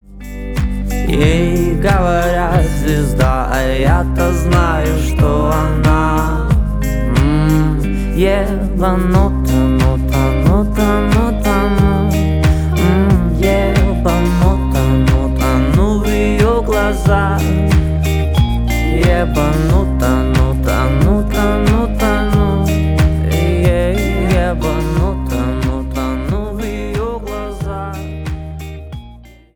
поп
спокойные
медленные